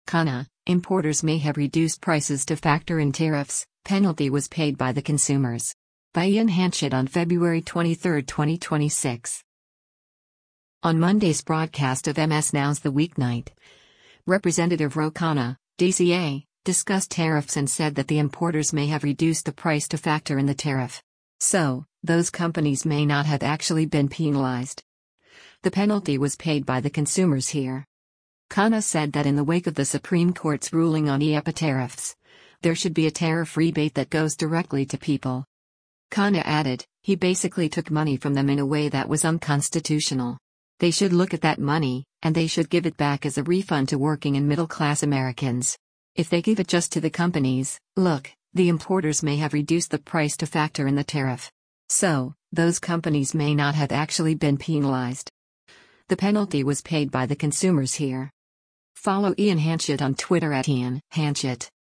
On Monday’s broadcast of MS NOW’s “The Weeknight,” Rep. Ro Khanna (D-CA) discussed tariffs and said that “the importers may have reduced the price to factor in the tariff. So, those companies may not have actually been penalized. The penalty was paid by the consumers here.”